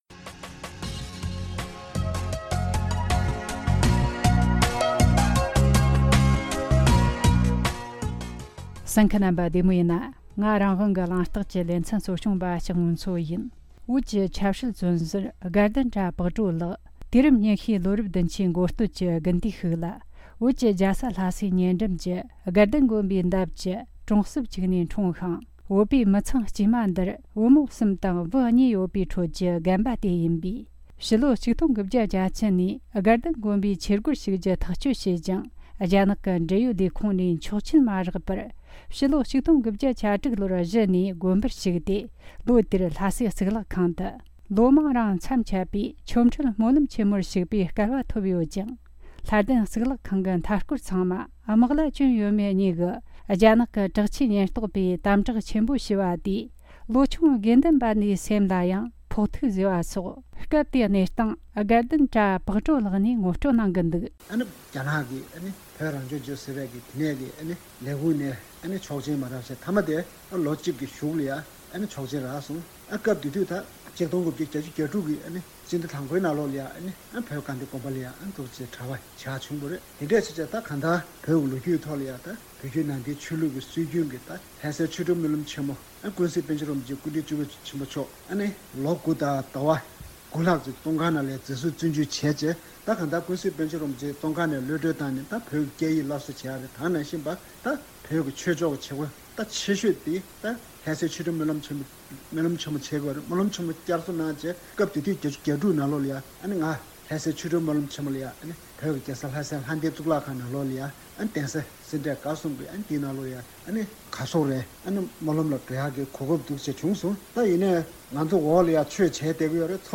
གླེང་མོལ་བྱས་བར་གསན་རོགས་གནོངས།